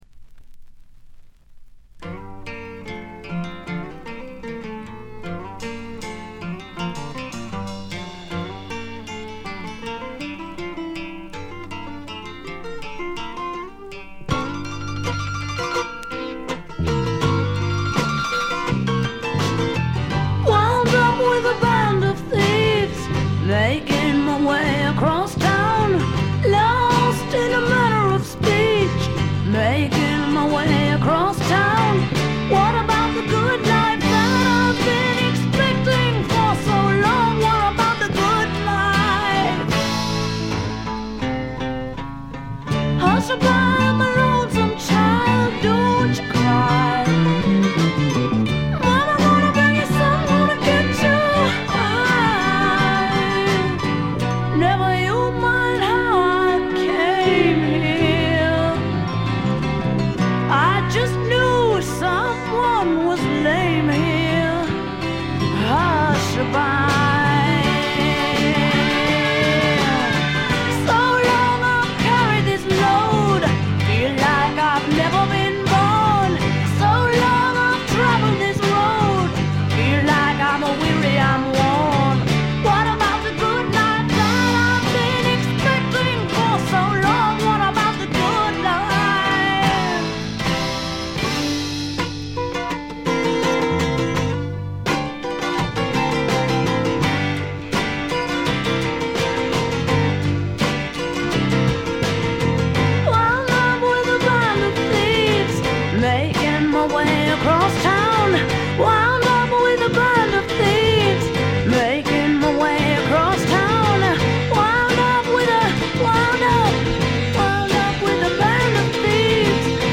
静音部での軽微なチリプチ。
カナダ出身の女性シンガーソングライターが残したサイケ／アシッド・フォークの大傑作です。
試聴曲は現品からの取り込み音源です。